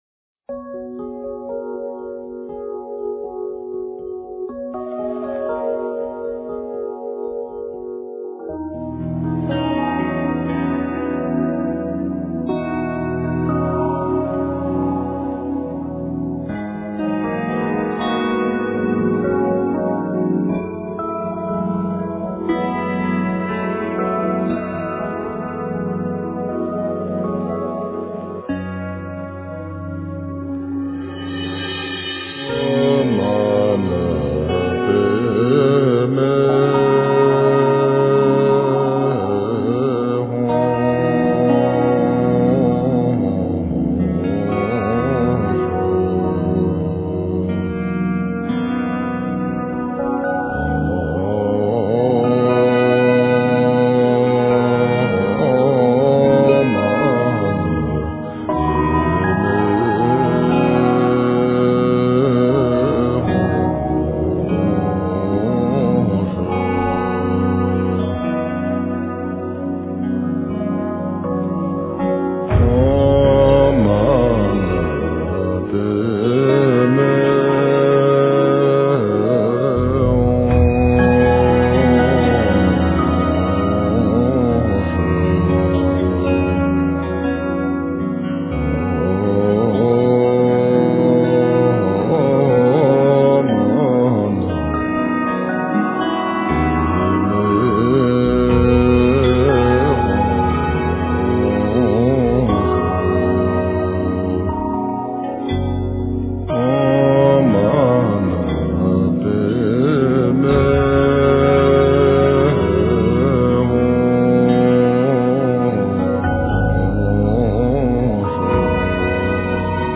标签: 佛音真言佛教音乐